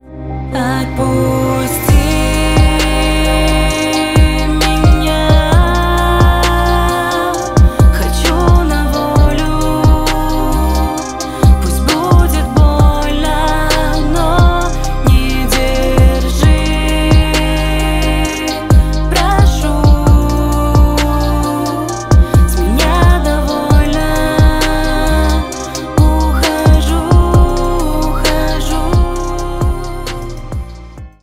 бесплатный рингтон в виде самого яркого фрагмента из песни
Поп Музыка
грустные